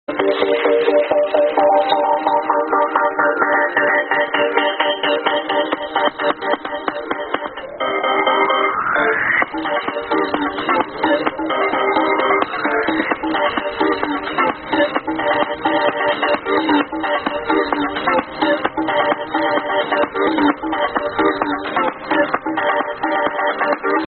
Pooooooooooooor quality.